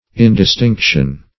Indistinction \In`dis*tinc"tion\
([i^]n`d[i^]s*t[i^][ng]k"sh[u^]n), n. [Cf. F. indistinction.]